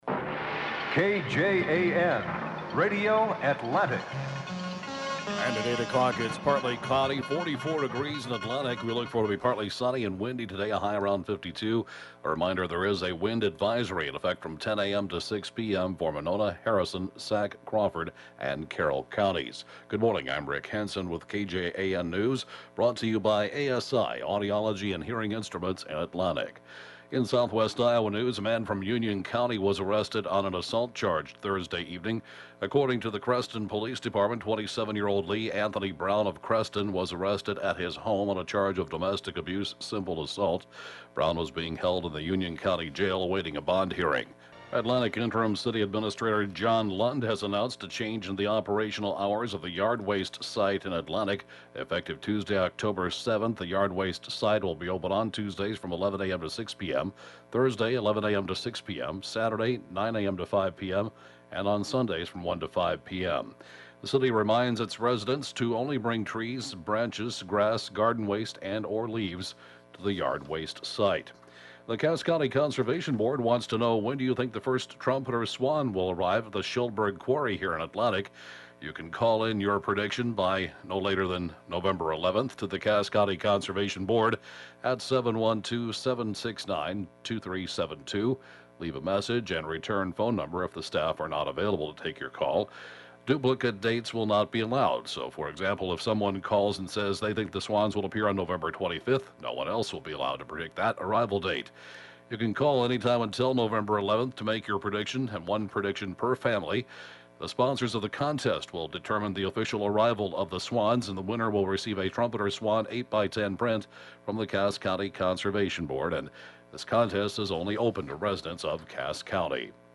(Podcast) 8-a.m. News, 10/3/2014